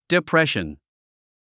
発音
dipréʃən　ディプレション